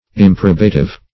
Meaning of improbative. improbative synonyms, pronunciation, spelling and more from Free Dictionary.